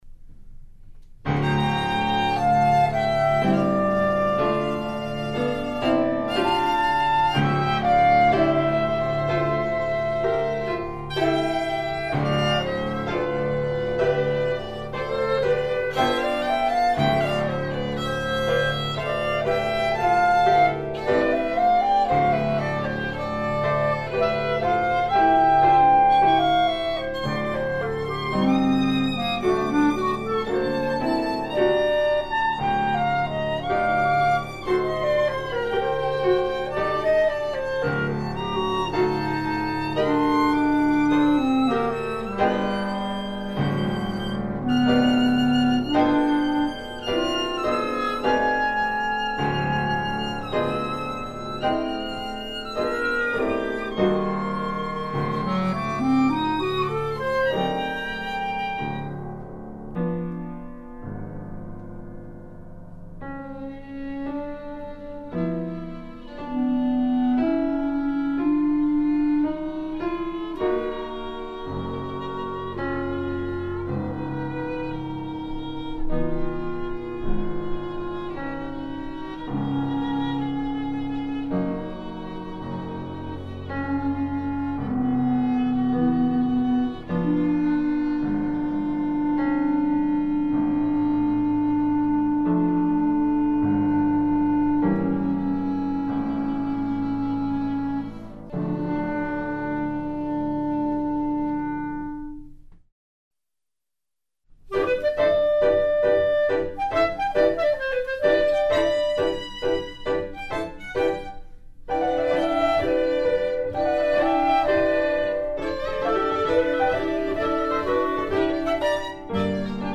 clarinet
Violin
Piano